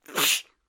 Tiếng cô gái Hắt hơi
Thể loại: Tiếng con người
Description: Tiếng cô gái Hắt hơi là âm thanh con người hắt xì hơi khi bị cảm lạnh, cảm cúm, âm thanh đau ốm khi sổ mũi hoặc người dị ứng phấn hoa bị dị ứng thời tiết, tiếng con gái hắt xì hơi một tiếng là tiếng phụ nữ khi đau ốm, tiếng hắt xì khi cơ thể đến nơi lạnh và chưa thích nghi được với nhiệt độ môi trường, âm thanh con người hắt xì hơi thường gặp vào mùa đông khi thời tiết lạnh.
Tieng-co-gai-hat-hoi-www_tiengdong_com.mp3